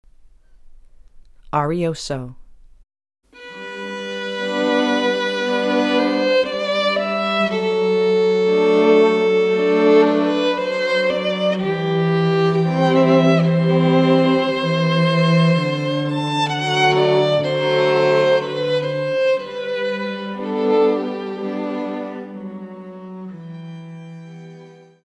The Music of The Woodvale String Quartet
In addition, we have numerous arrangements, for string quartet and singer, of the most common pieces of music used in the Catholic ceremony and Mass.